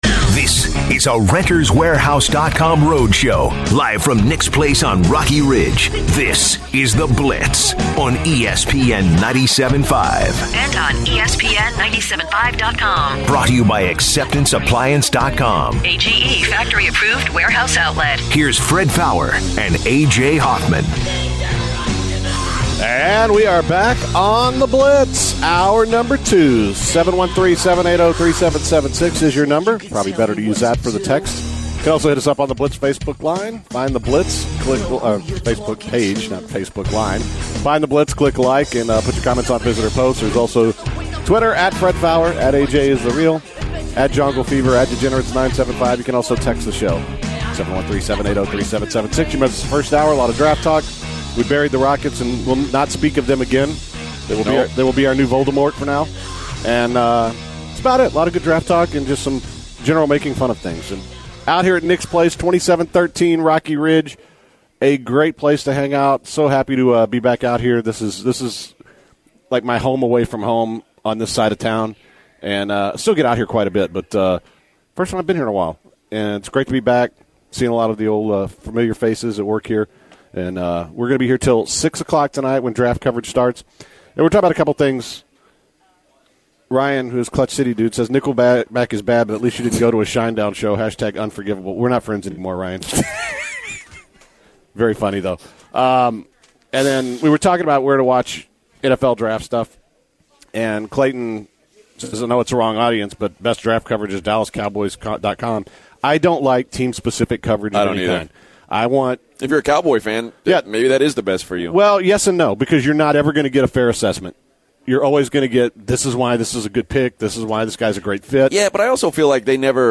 live from Nick’s Place